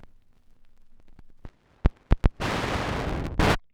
vinyl needle scratching effect - skating.wav
Sound effects
vinyl_needle_scratching_effect_EdI.wav